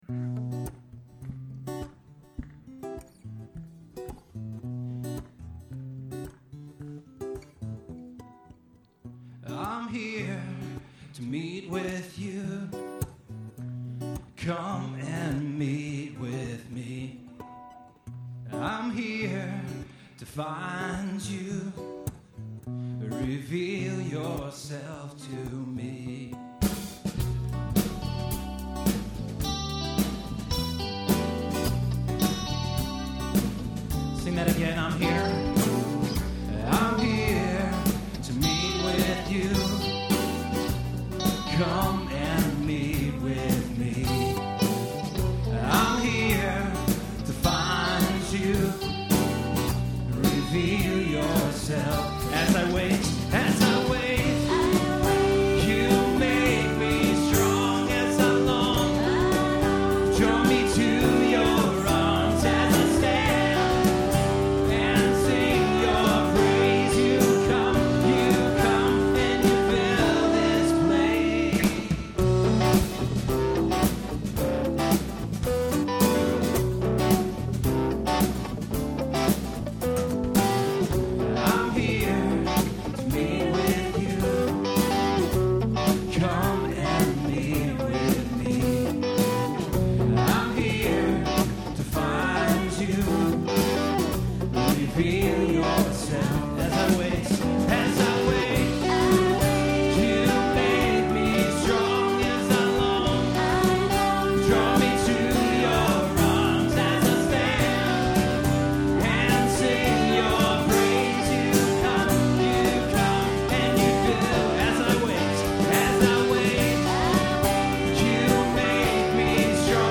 Performed live at Terra Nova - Troy on 10/26/08.